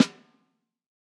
Snare Zion 7.wav